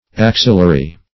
Axillary - definition of Axillary - synonyms, pronunciation, spelling from Free Dictionary
Axillary \Ax"il*la*ry\ ([a^]ks"[i^]l*l[asl]*r[y^]), a. [See